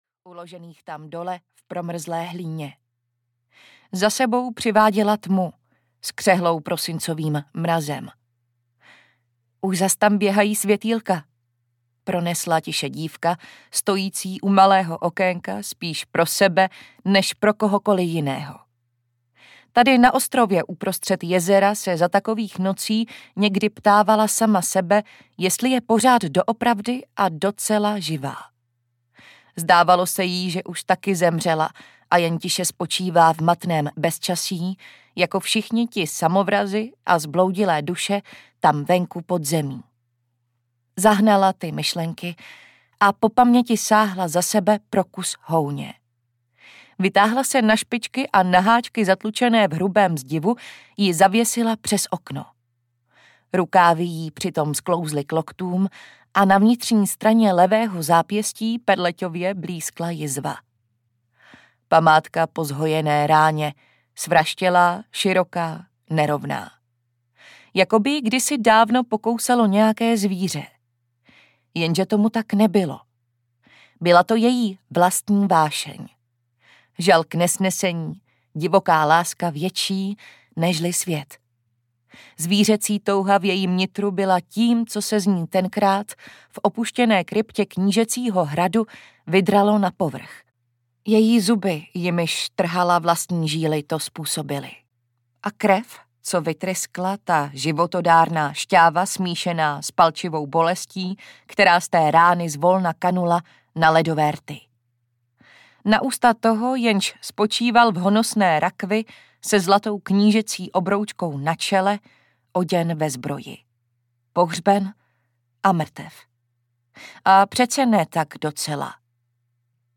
Mráz v kostech audiokniha
Ukázka z knihy